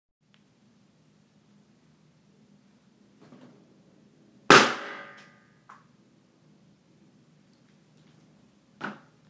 Bursting Ballon
ballon ballon-bursting Balloon Bang bursting dry-walls living-room Pop sound effect free sound royalty free Sound Effects